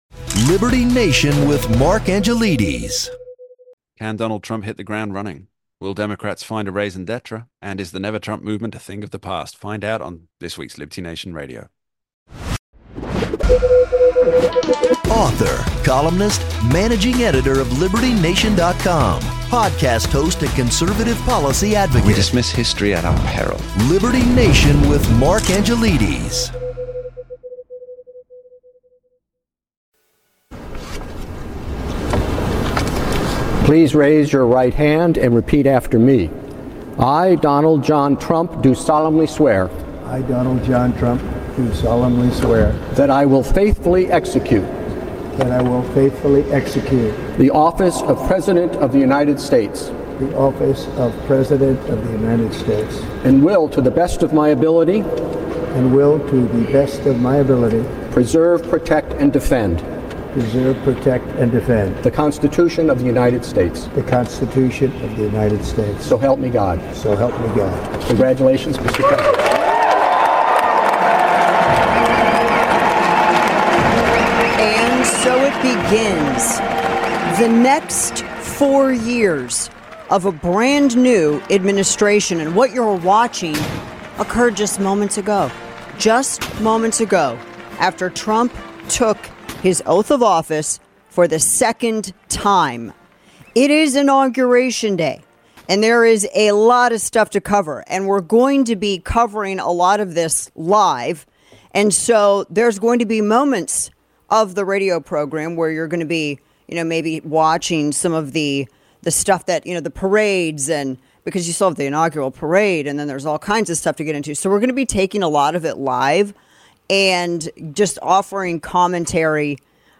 President Trump gives his first address live. Dana breaks down the list of Trump's immediate executive actions.